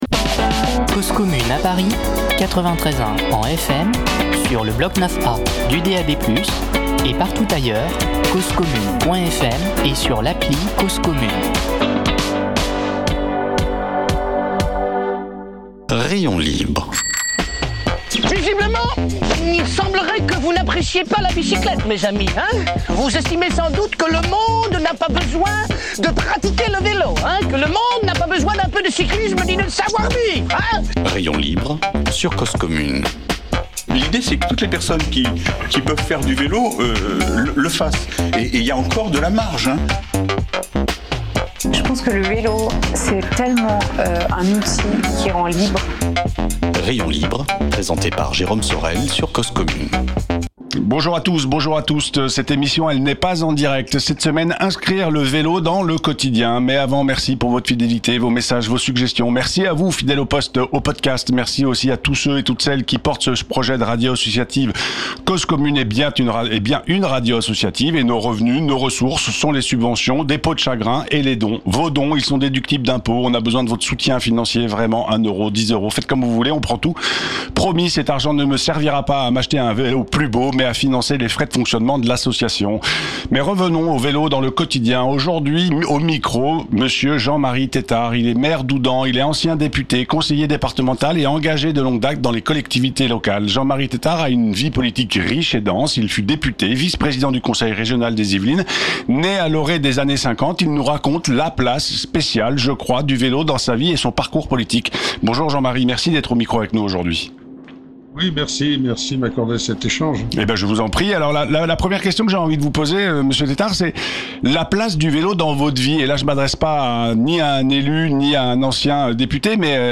En plateau Jean-Marie Tétart Jean-Marie Tétart est Maire d’Houdan, ancien député, conseiller départemental et enga